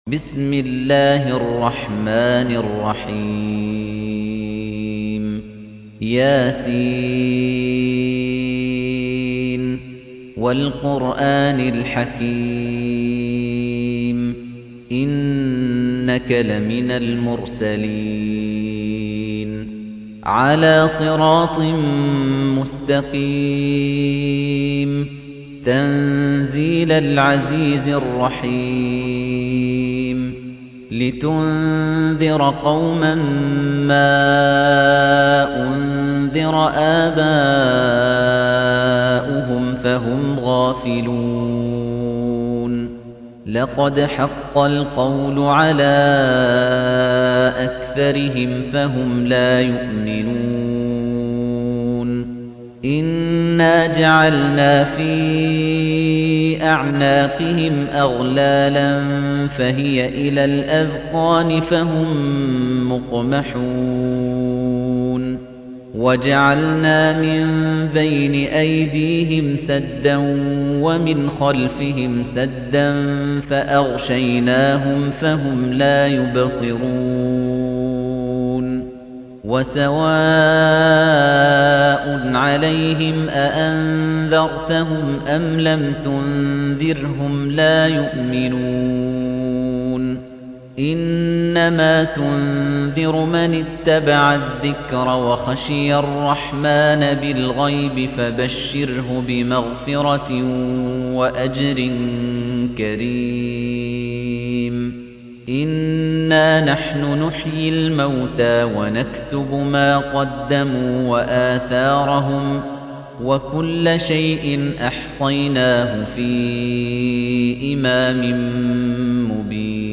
تحميل : 36. سورة يس / القارئ خليفة الطنيجي / القرآن الكريم / موقع يا حسين